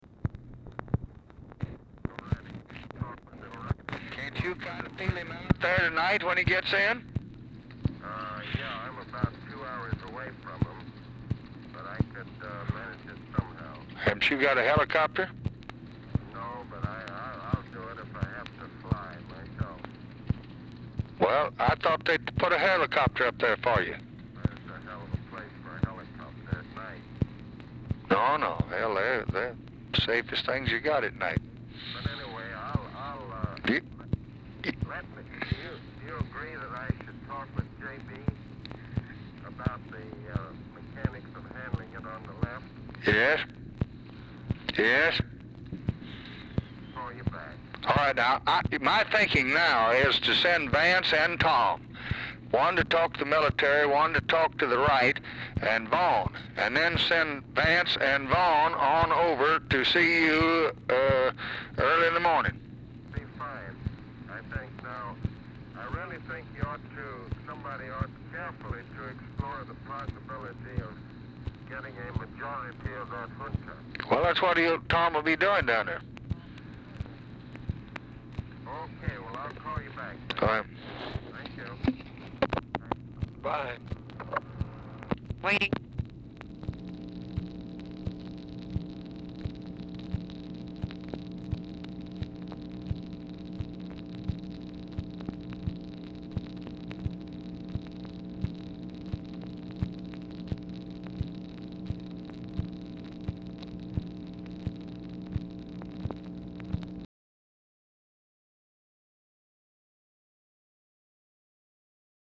Telephone conversation # 7687, sound recording, LBJ and ABE FORTAS, 5/14/1965, 8:45PM | Discover LBJ
Format Dictation belt
Location Of Speaker 1 Oval Office or unknown location
Specific Item Type Telephone conversation Subject Defense Diplomacy Latin America